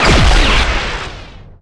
fire_plasma2.wav